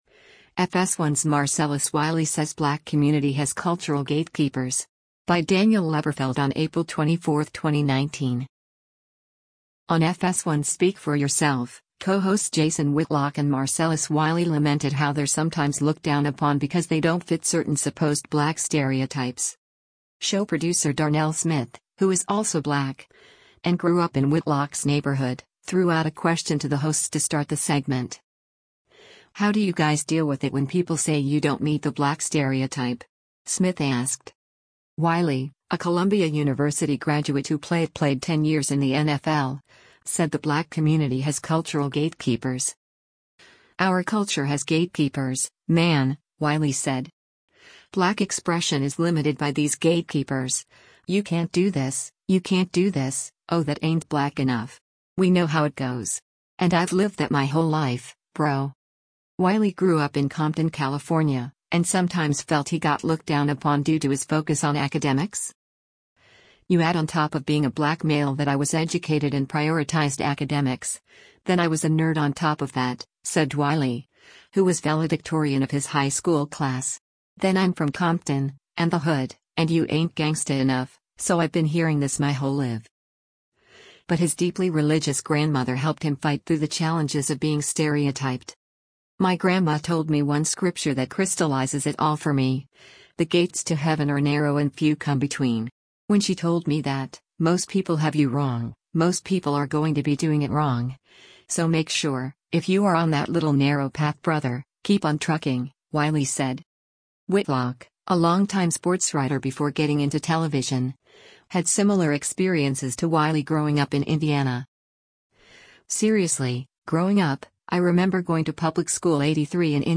On FS1’s Speak for Yourself, co-hosts Jason Whitlock and Marcellus Wiley lamented how they’re sometimes looked down upon because they don’t fit certain supposed black stereotypes.